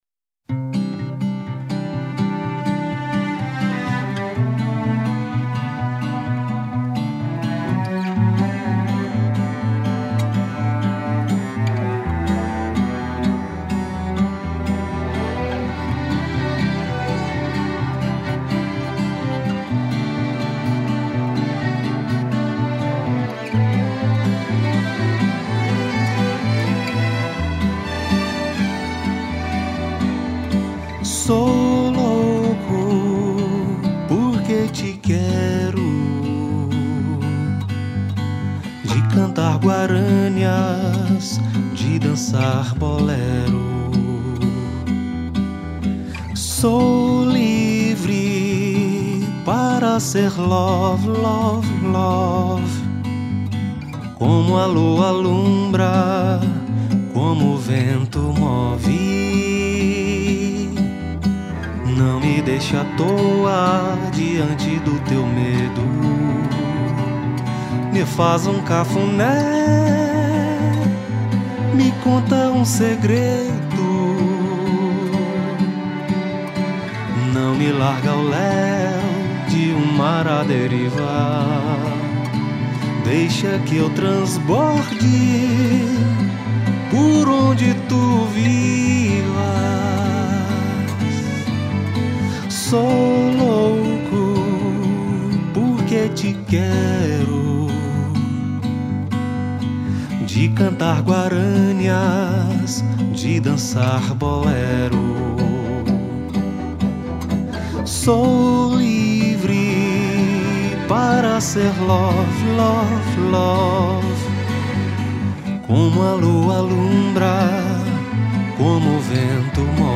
1277   05:09:00   Faixa:     Rock Nacional